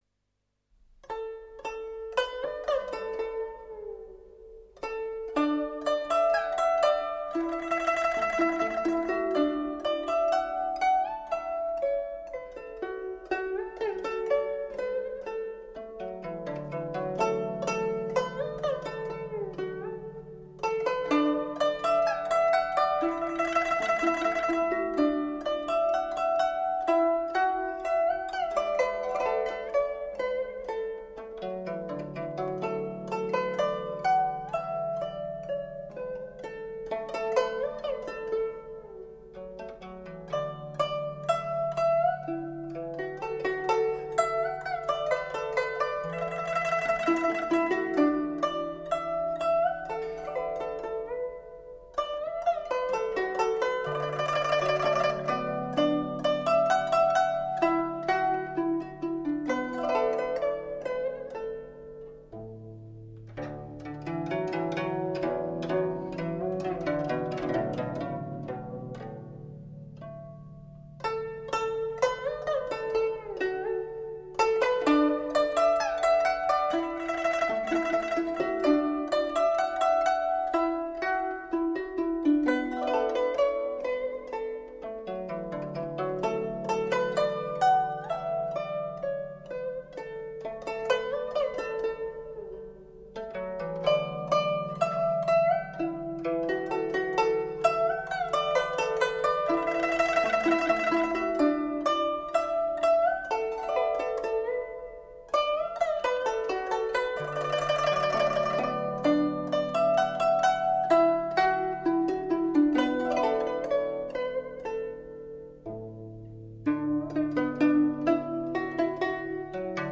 古筝演奏
记忆中总是有那么多属于那个红色时代里的的旋律，动听而又激情燃烧，古筝的纯净激昂，重新演绎这些歌，让我们重温那个时代